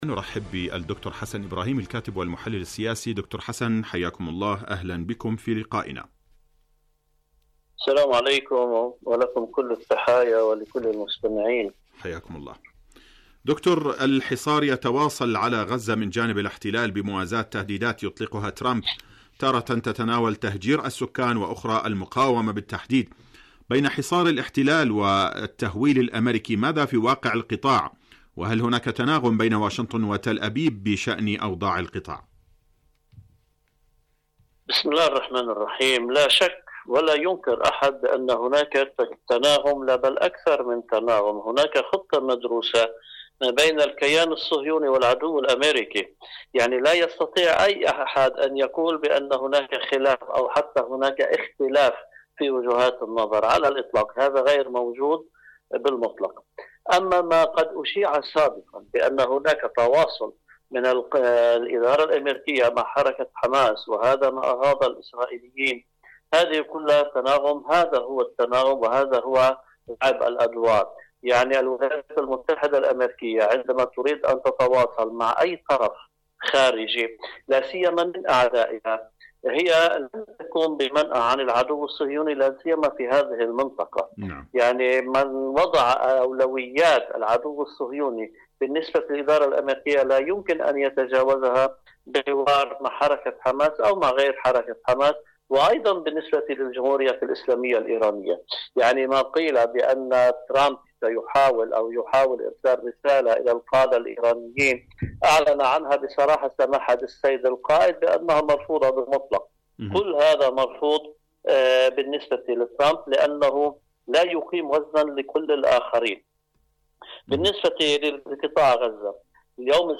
فلسطين اليوم: مقابلة إذاعية